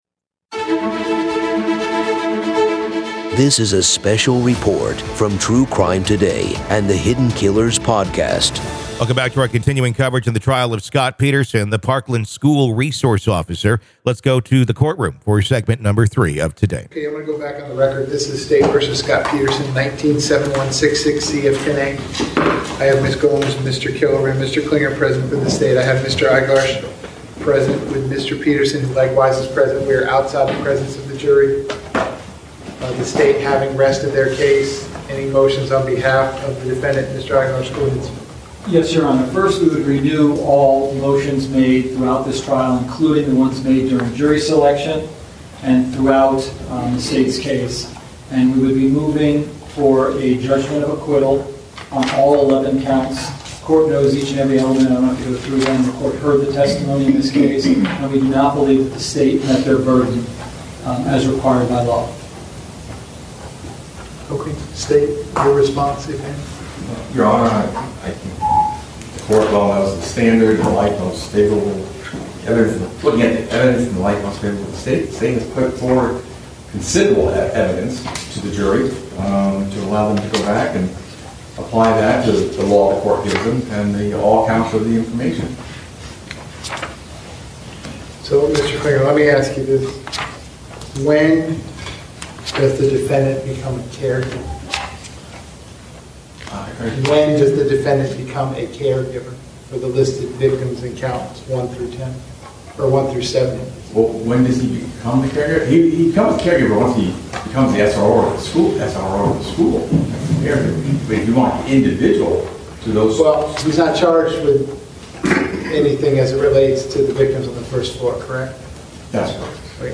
In this episode, we continue our live coverage of the tense courtroom drama unfolding at the trial of Scot Peterson, the Parkland School resource officer accused of neglecting his duty during a deadly school shooting.